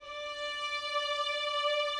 strings_062.wav